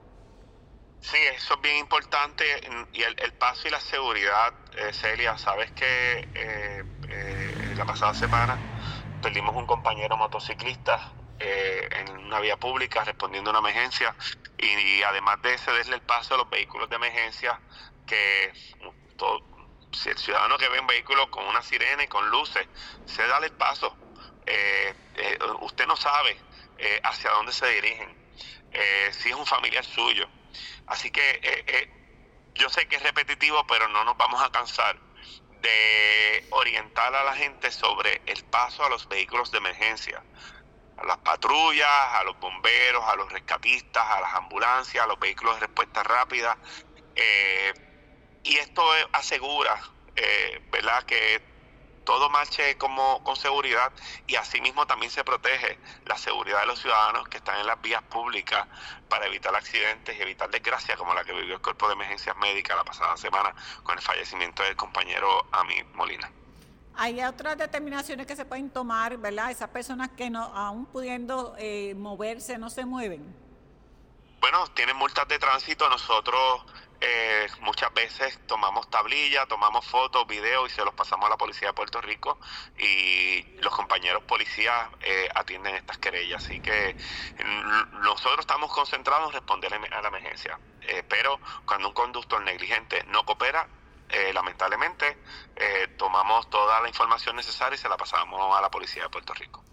“Ellos se dirigen a salvar vidas” Titular de Emergencias Médicas hace llamado a ceder el paso a los vehículos de respuestas rápida (sonido)